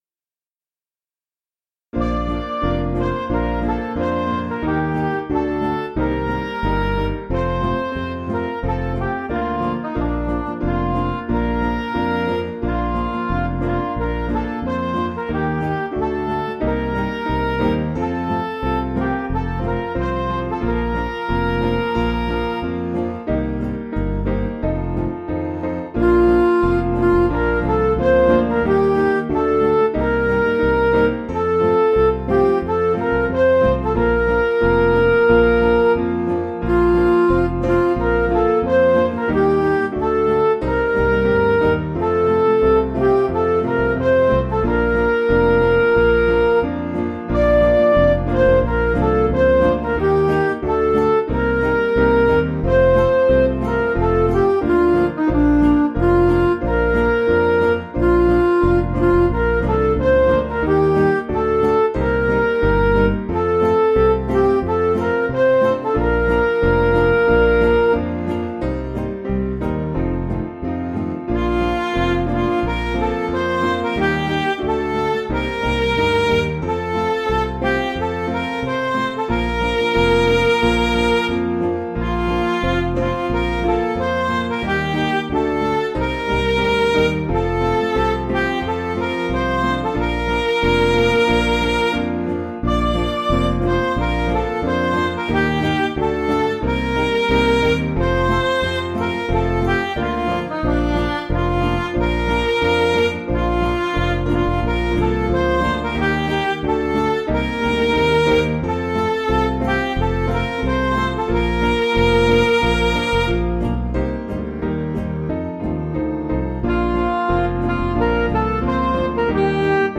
Piano & Instrumental
(CM)   5/Bb
Midi